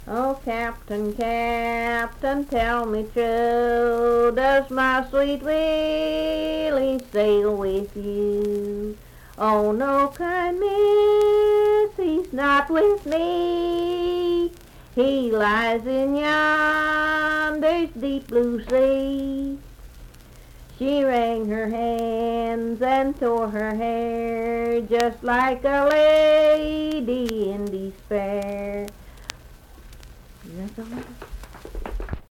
Sweet Willie - West Virginia Folk Music | WVU Libraries
Unaccompanied vocal music
Voice (sung)